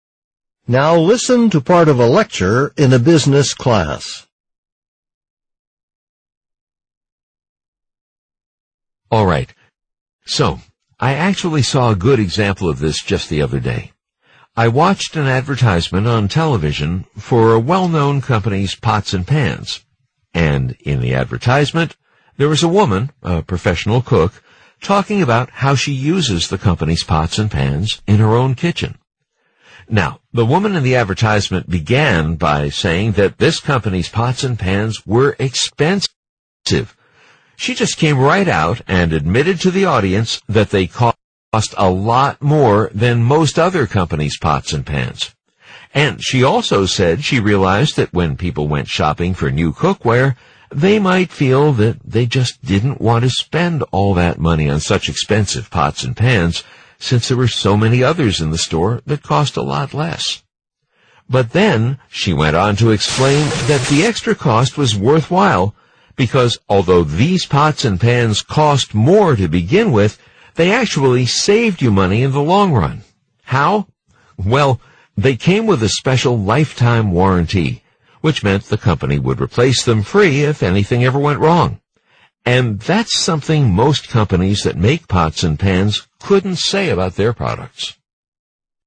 tpo28_speaking4_question4_dialog.mp3